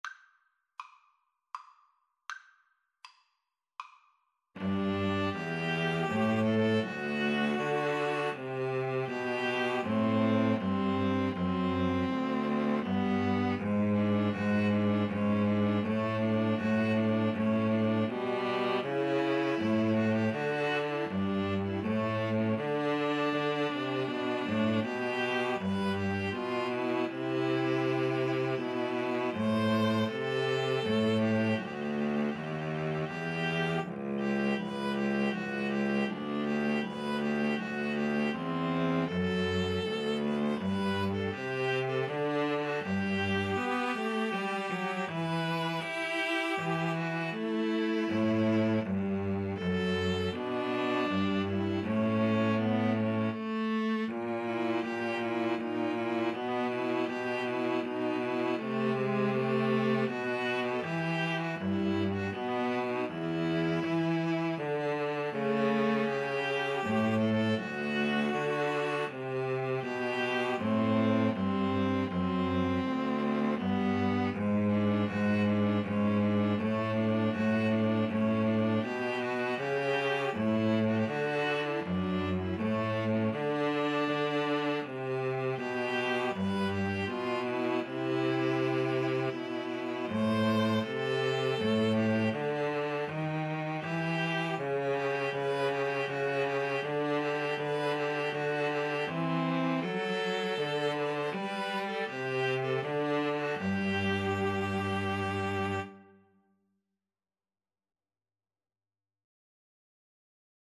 Andante
Classical (View more Classical String trio Music)